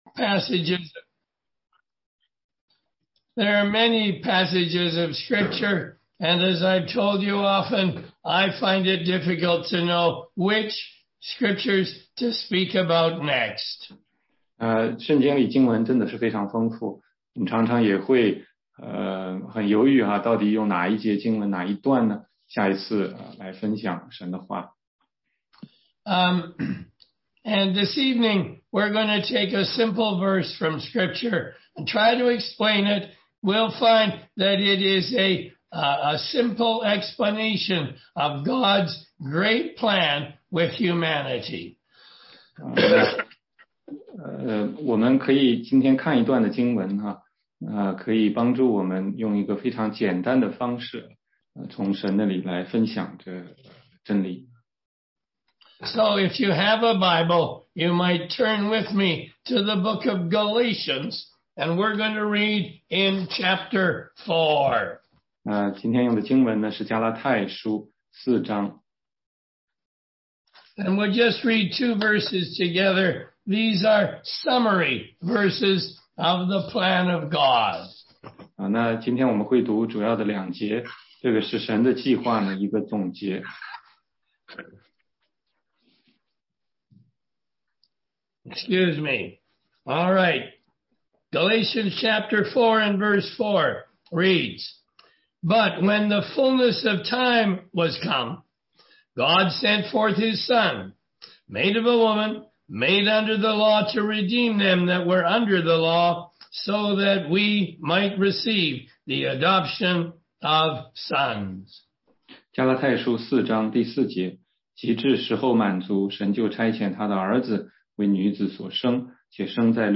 16街讲道录音 - 福音课第六十一讲（中英文）